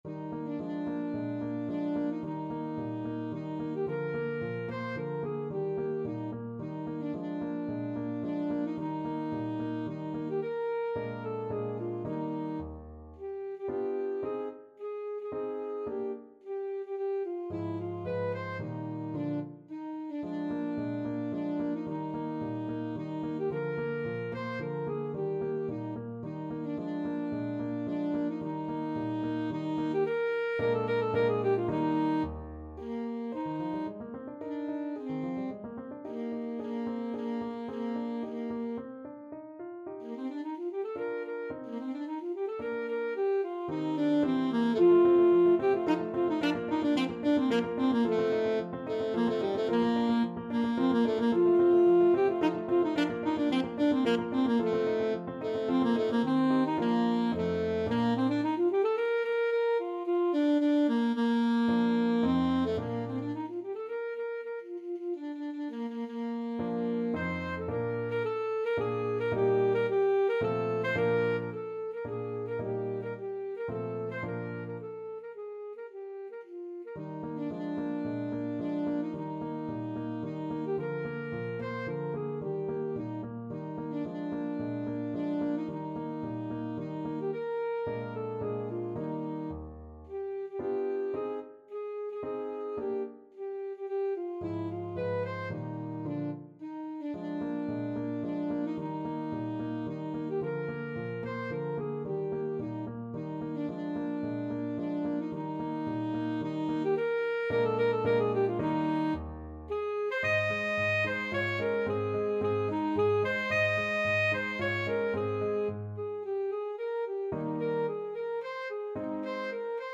Alto Saxophone
Eb4-F6
3/4 (View more 3/4 Music)
Classical (View more Classical Saxophone Music)